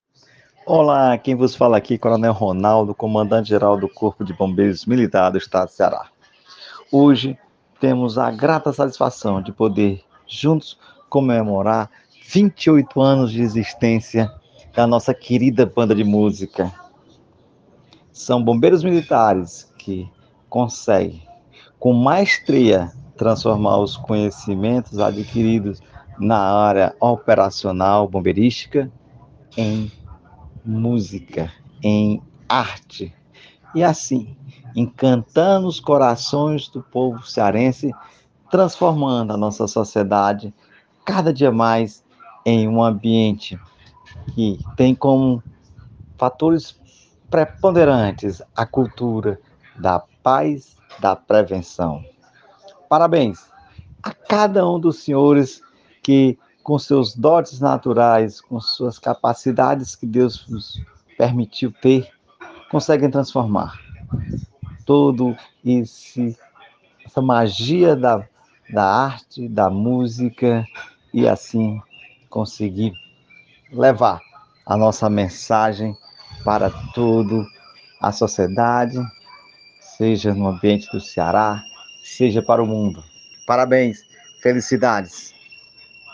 Aniversário de 28 anos da Banda de Música do Corpo de Bombeiros do Ceará
Palavras do Coronel Comandante-Geral do CBMCE,  Ronaldo Roque